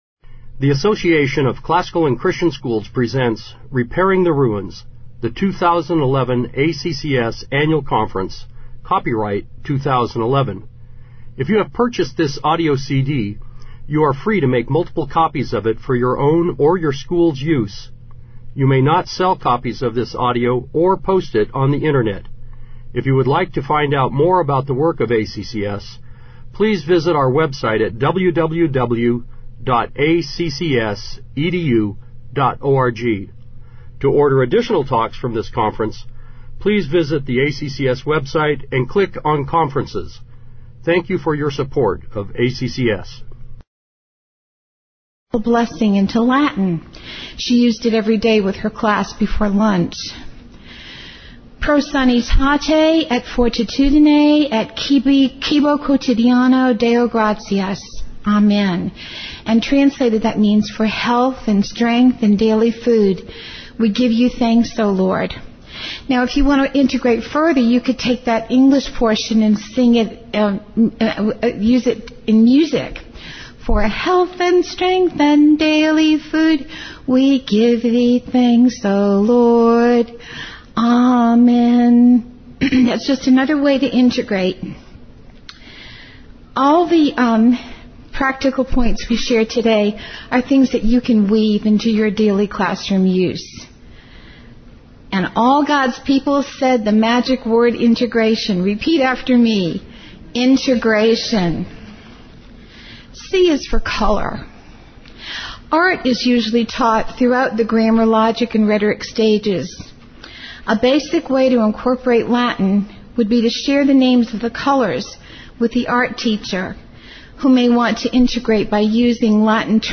2011 Workshop Talk | 0:45:12 | All Grade Levels, Latin, Greek & Language